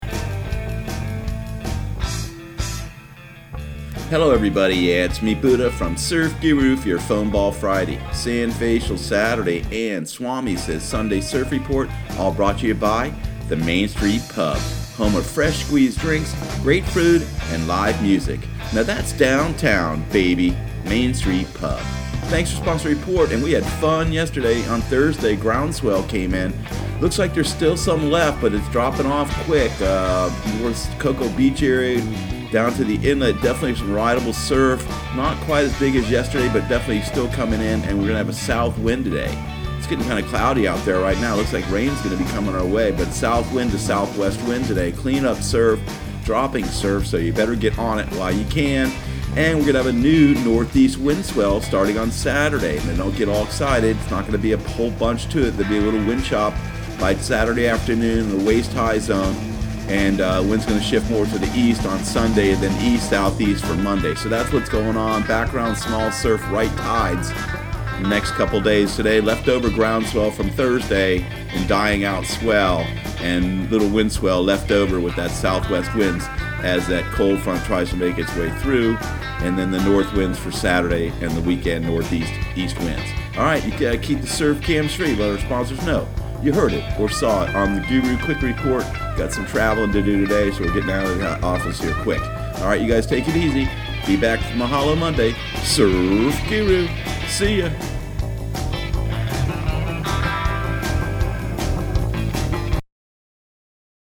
Surf Guru Surf Report and Forecast 04/26/2019 Audio surf report and surf forecast on April 26 for Central Florida and the Southeast.